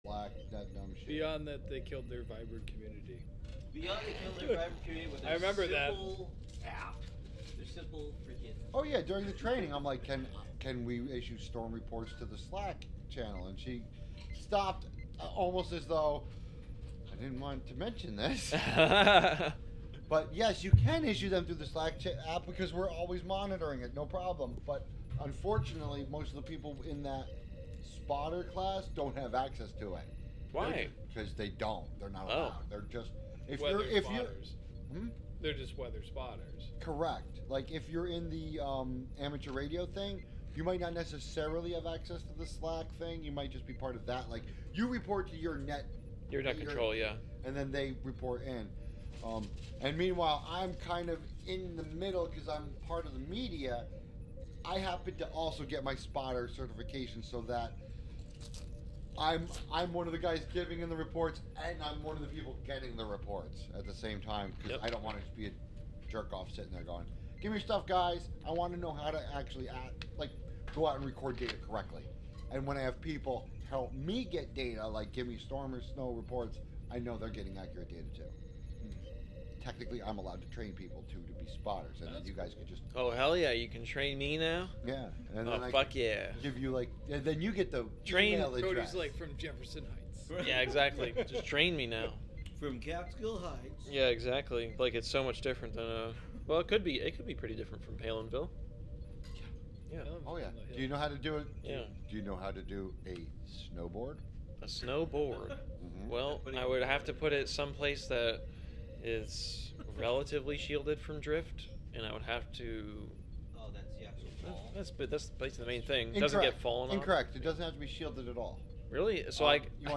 Live from the Thingularity Studios: Thingularity (Audio) Nov 02, 2023 shows Live from the Thingularity Studios LIVE from the Dog Pen of Catskill Heights Play In New Tab (audio/mpeg) Download (audio/mpeg)